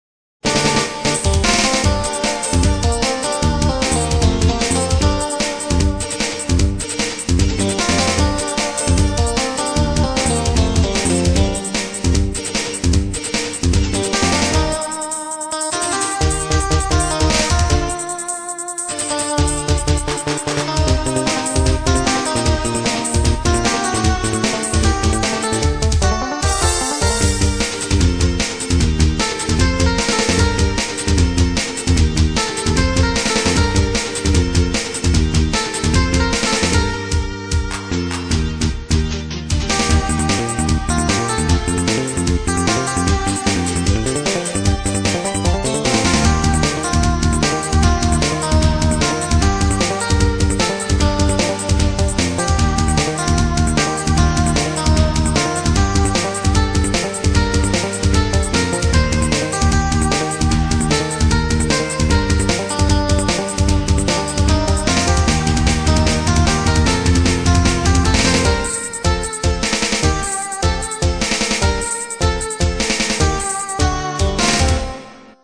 掲示板で話題になったので即席で耳コピーして、音色を適当に当てた直後のデータです。
※音源はＳＣ８８Ｐｒｏ